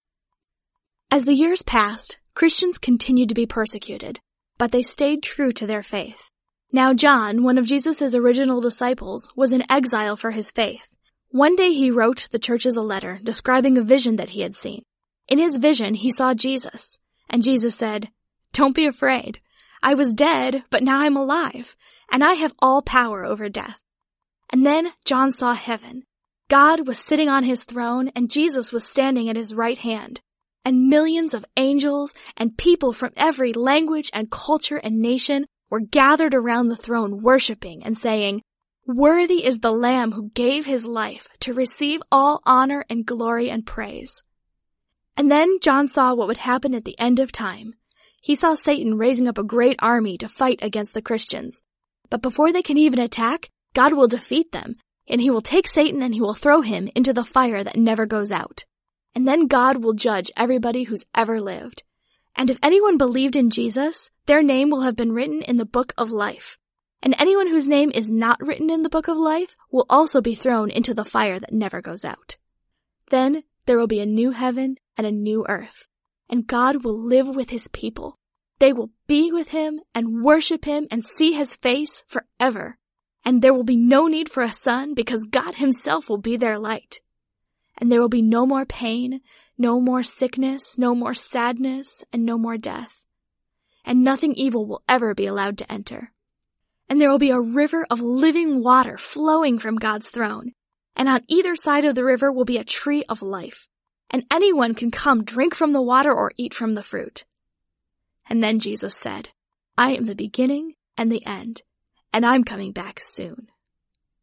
This Advent season, anticipate the celebration of Christmas with oral Bible stories.